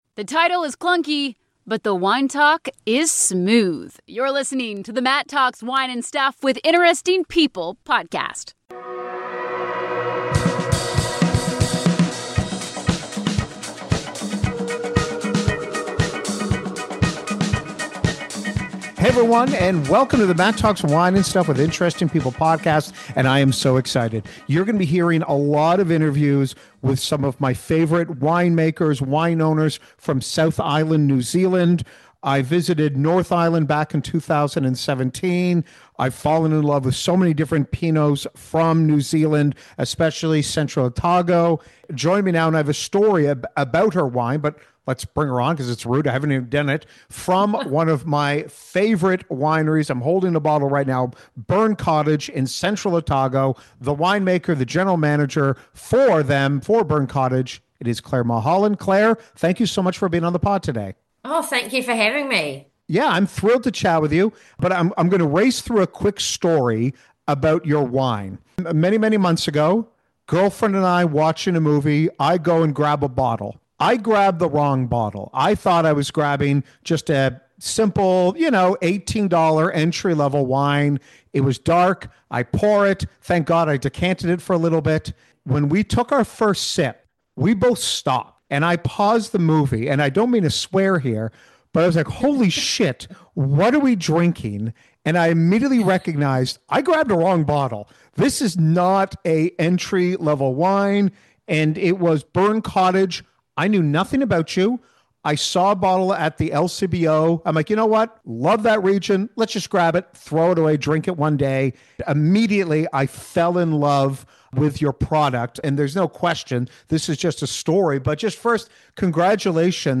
Located in the famed Central Otago wine region in South Island New Zealand, this winery has been producing elite Pinot for ages.